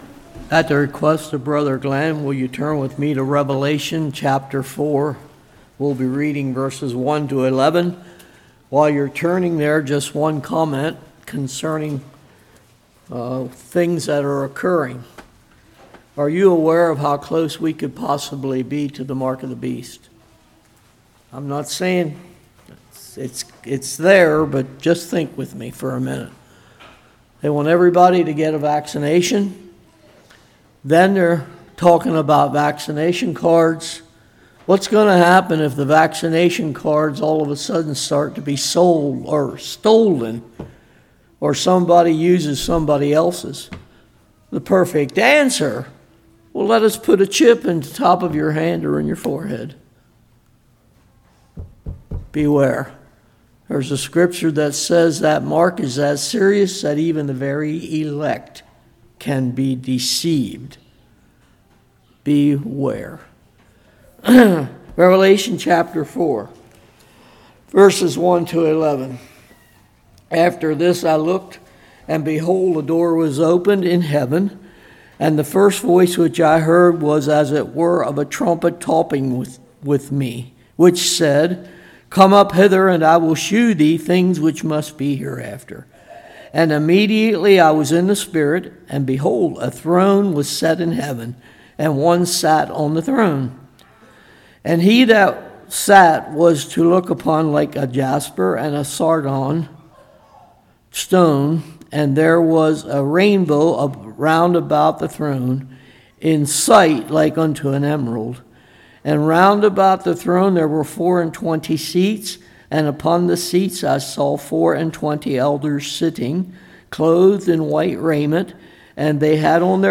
Passage: Revelation 4:1-11 Service Type: Morning